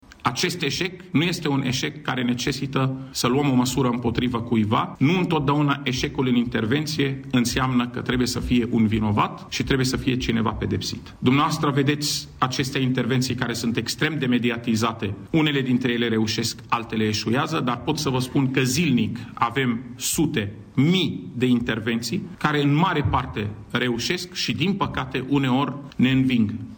Într-o conferință de presă susținută duminică după-amiază, secretarul de stat Raed Arafat a amintit că în București nu există o pernă golflabilă, pentru că toate sunt casate.
Raed Arafat a mai spus că în acest caz nu va fi nimeni sancționat: